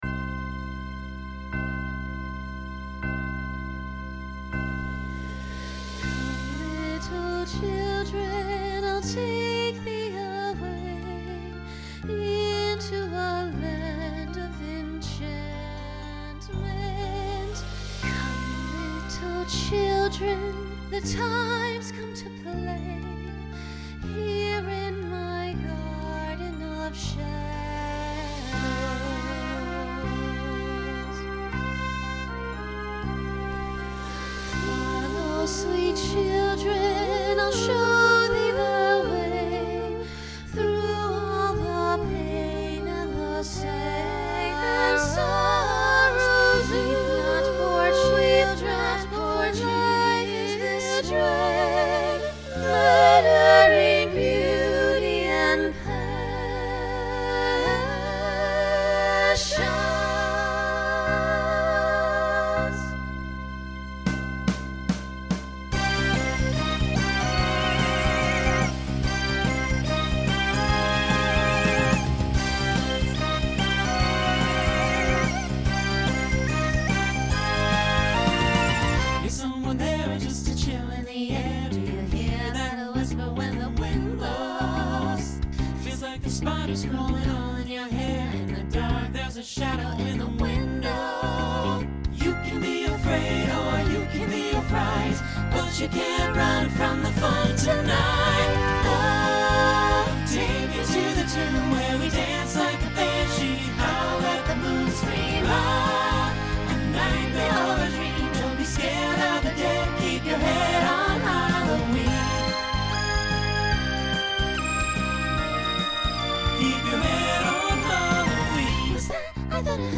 Broadway/Film , Pop/Dance
Story/Theme Voicing SATB